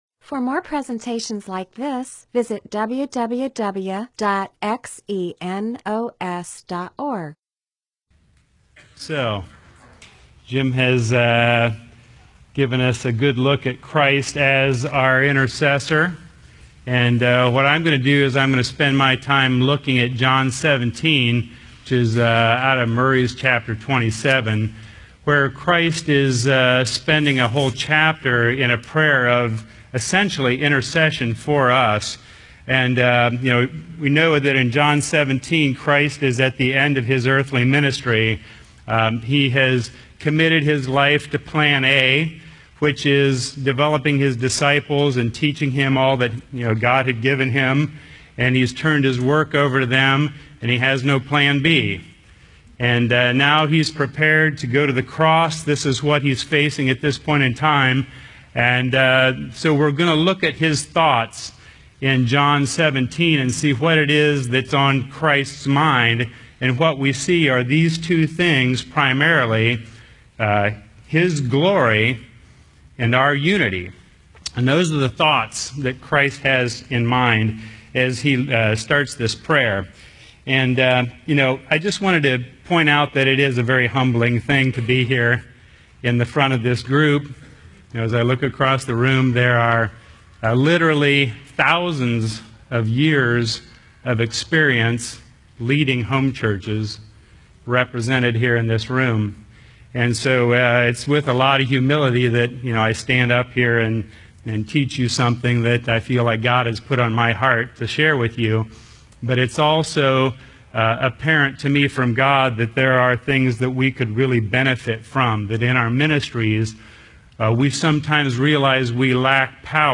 MP4/M4A audio recording of a Bible teaching/sermon/presentation about John 17:1-22; Revelation 5:3-14.